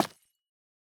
Minecraft Version Minecraft Version 21w07a Latest Release | Latest Snapshot 21w07a / assets / minecraft / sounds / block / calcite / break3.ogg Compare With Compare With Latest Release | Latest Snapshot
break3.ogg